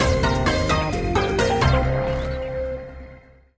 brawly_biome_loading_01.ogg